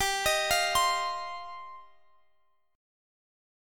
Listen to G7sus4#5 strummed